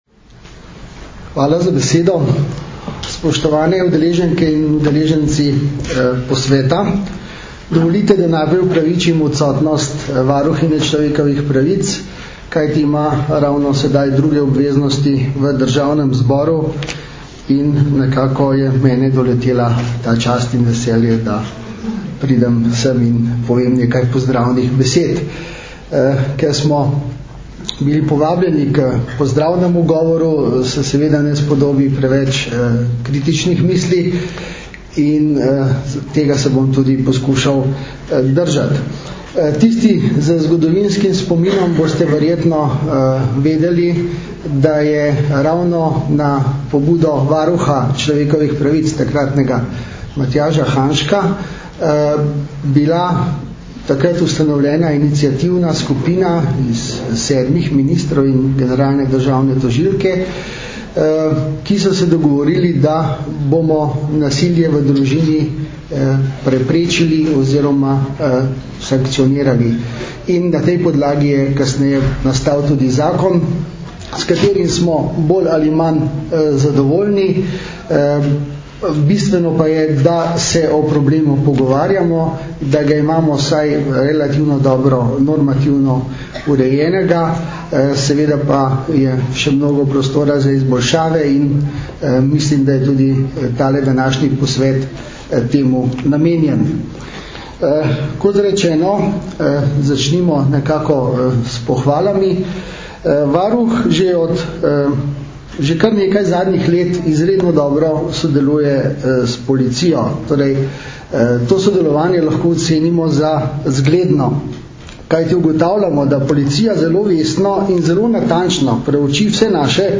Na Brdu pri Kranju se je danes, 12. aprila 2012, začel dvodnevni strokovni posvet "Nasilje nad otroki – že razumemo?", ki ga letos že enajstič organizirata Generalna policijska uprava in Društvo državnih tožilcev Slovenije v sodelovanju s Centrom za izobraževanje v pravosodju.
Zvočni posnetek nagovora namestnika varuhinje človekovih pravic Toneta Dolčiča (mp3)